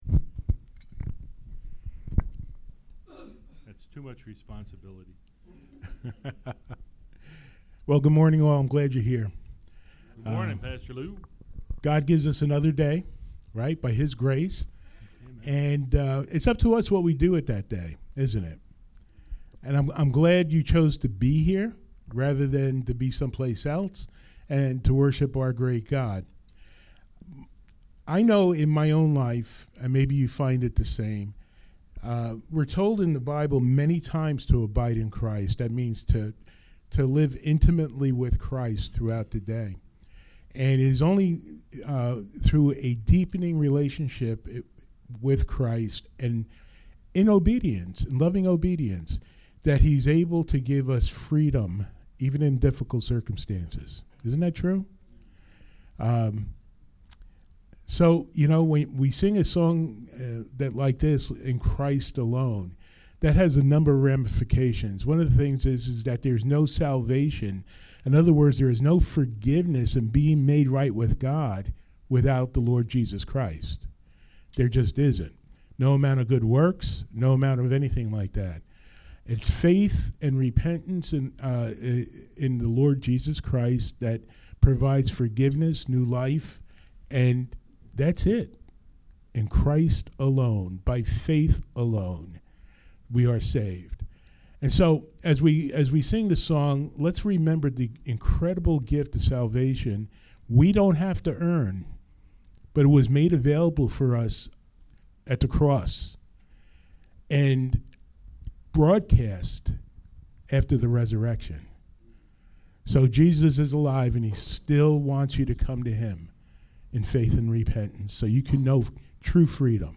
Series: Sunday Morning Worship